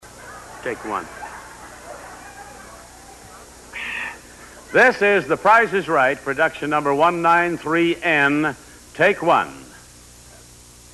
CLICK HERE TO LISTEN TO THE ONE AND ONLY JOHNNY OLSON!